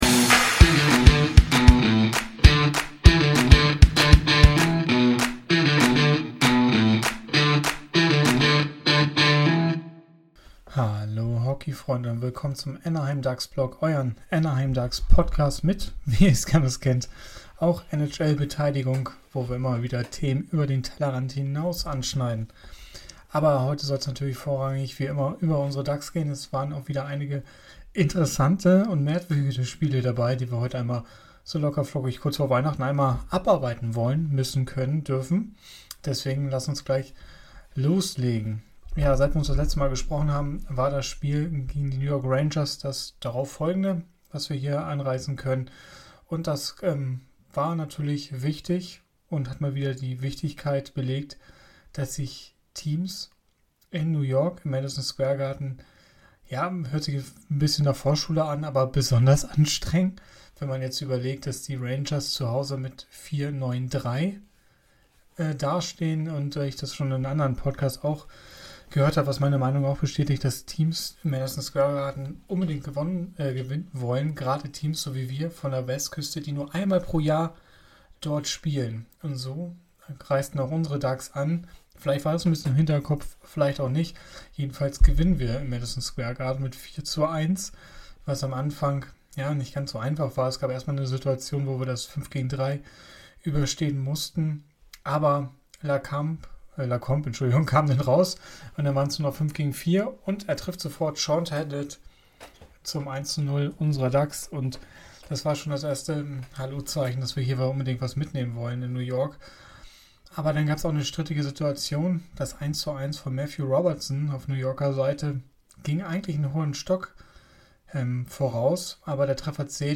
Hallo Hockeyfreunde, mit angeschlagener Stimme besprechen wir heute die jüngsten Spiele der Ducks und analysieren die aktuelle Situation. Dazu schauen wir genau auf die Tabelle und besprechen die Wichtigkeit der nächsten Spiele.